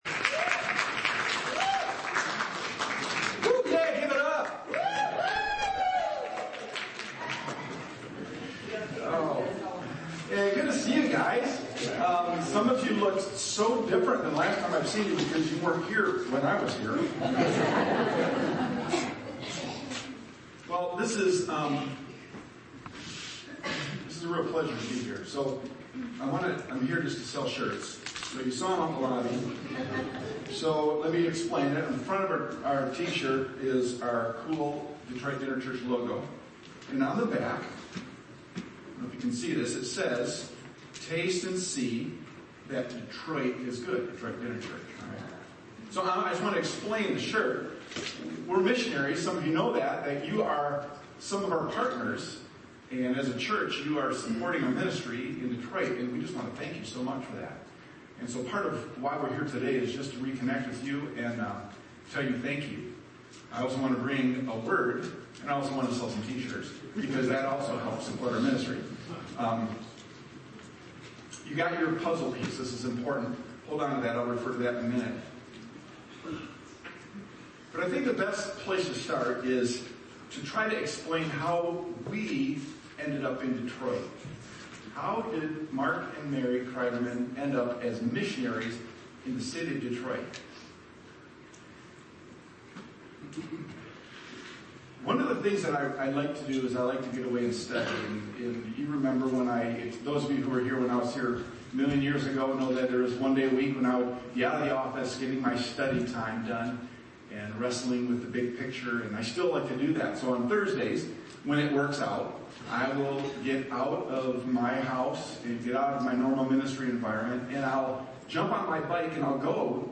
1.5.20-Reaching-the-Margins-Sermon-Audio.mp3